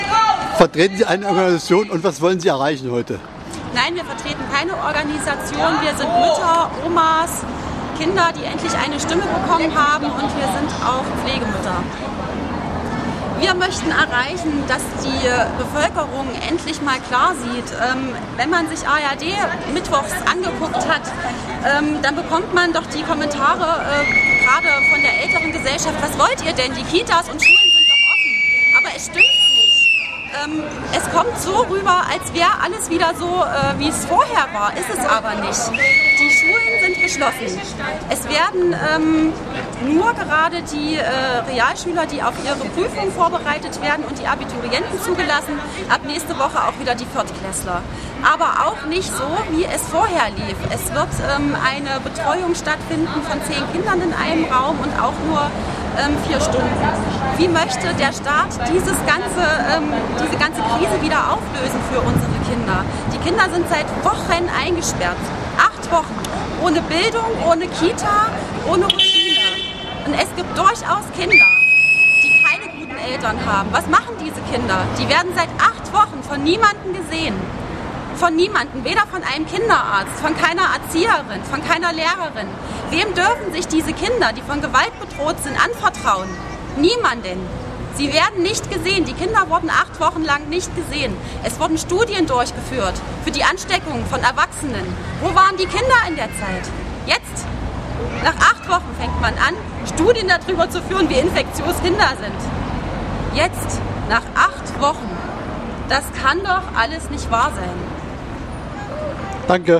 Die Aktion fand in der Bebrastraße von Sondershausen statt.
Die Aktion wurde mit Plakaten (siehe Bildergalerie), Musik und Aufrufen begleitet. Mit Trillerpfeifen wurde auf die Aktion aufmerksam gemacht.